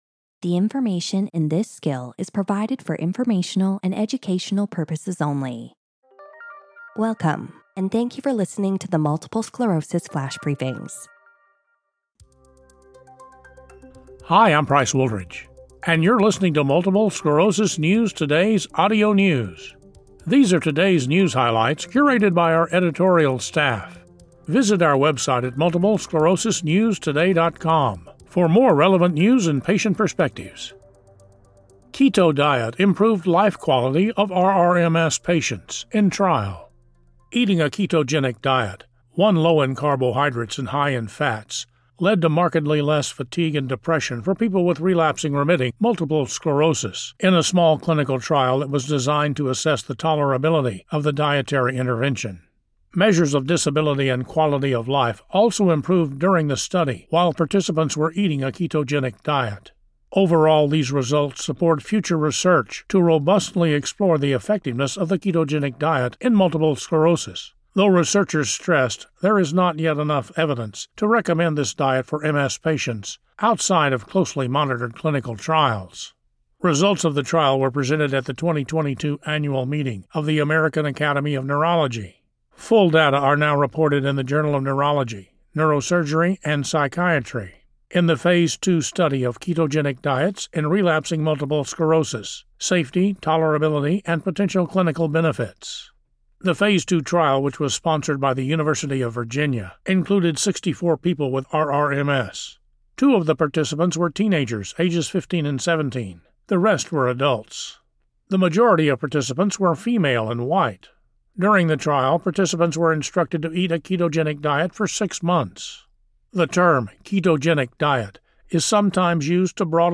reads a news article about how eating a ketogenic diet — low in carbohydrates and high in fats — led to less fatigue and depression for people with relapsing-remitting MS.